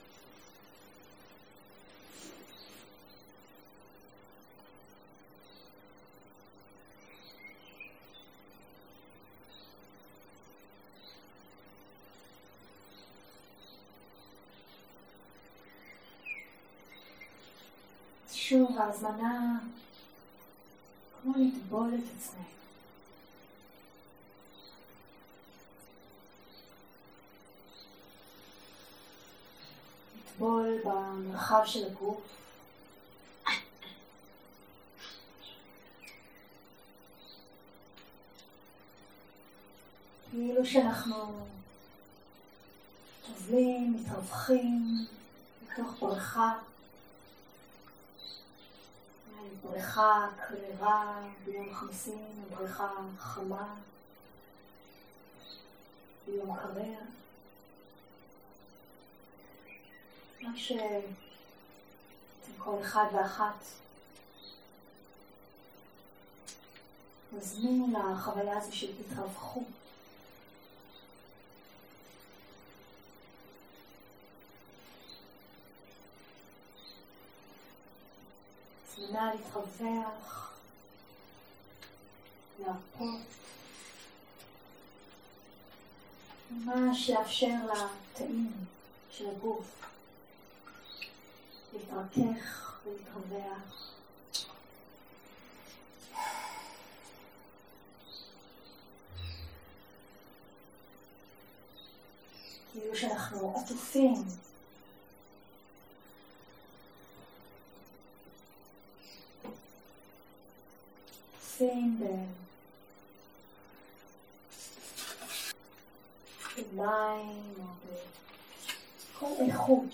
יום 3 - הקלטה 7 - צהרים - מדיטציה מונחית